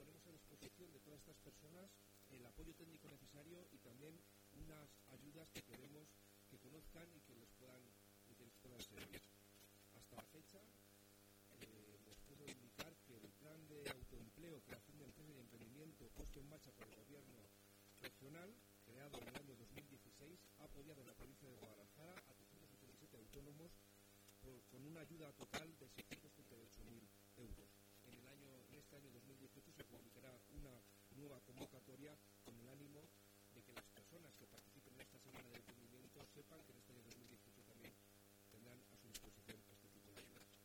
El delegado de la Junta en Guadalajara, Alberto Rojo, habla de las ayudas al emprendimiento otorgadas por el Gobierno regional.